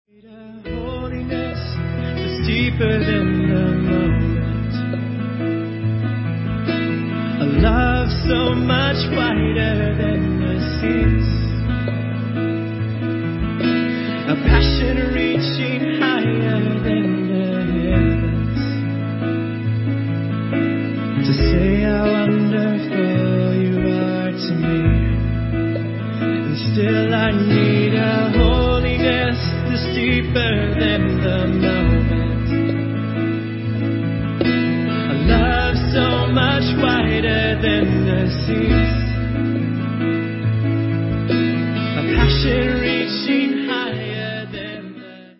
was recorded live in Portland, OR.